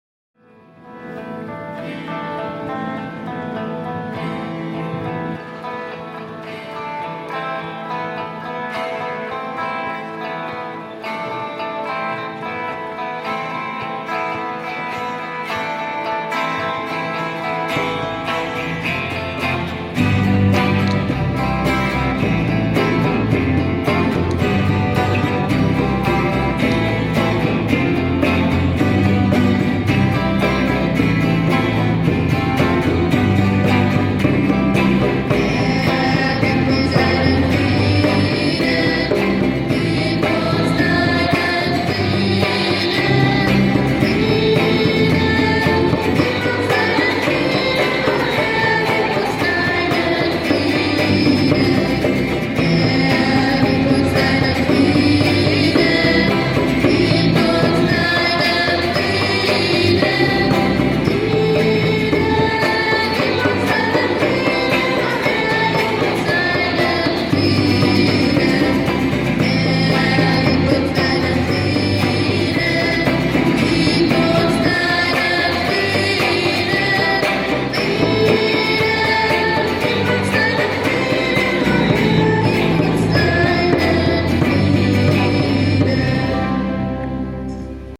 Nach dem Glockenspiel begann unser Part mit dem eigenen Gong:
Fast pünktlich, um 10 vor 6, beendeten wir unsere Versammlung auf dem Marienplatz und konnten mit einigen Menschen ins Gespräch kommen.